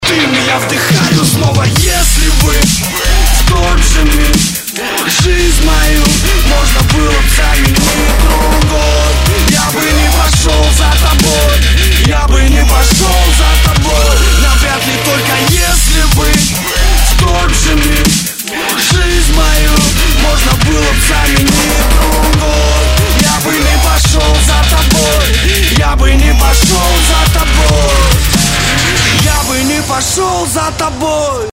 DubStep / Дабстеп